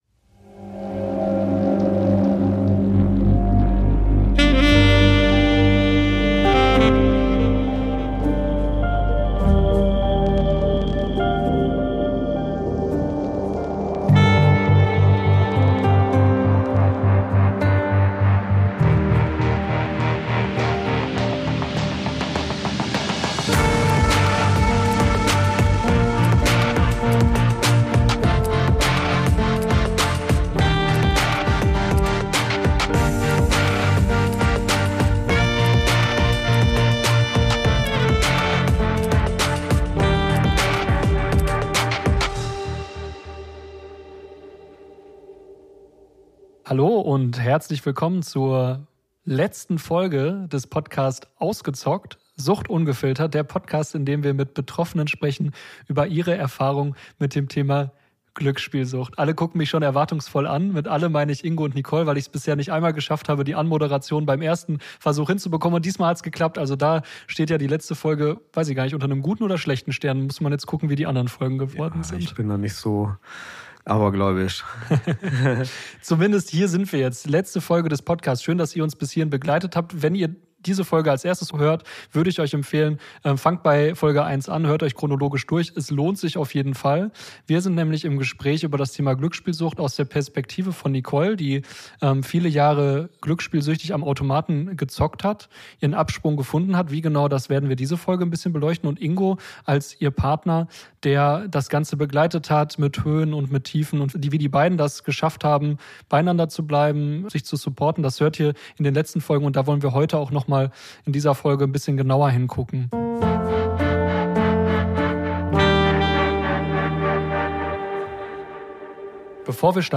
Folge 5: Von Löwen und dem Fels in der Brandung ~ Ausgezockt: Sucht ungefiltert - Betroffene im Gespräch Podcast